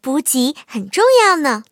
M22蝉补给语音.OGG